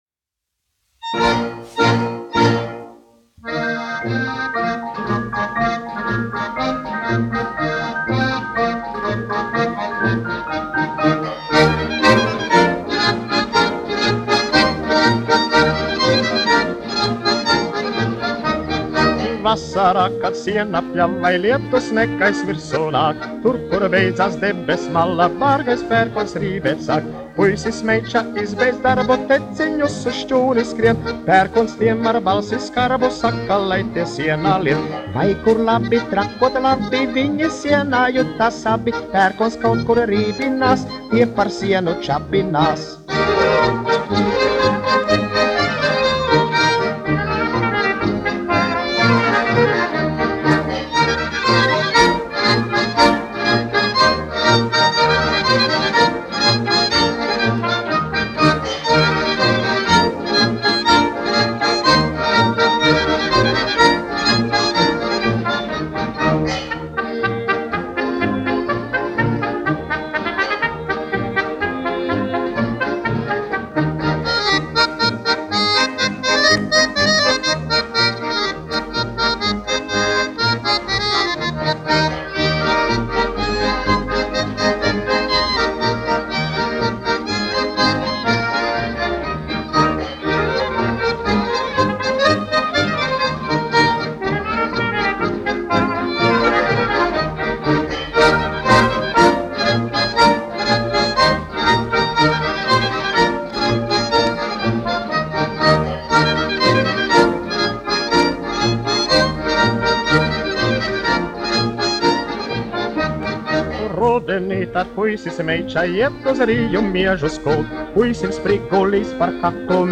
1 skpl. : analogs, 78 apgr/min, mono ; 25 cm
Populārā mūzika
Polkas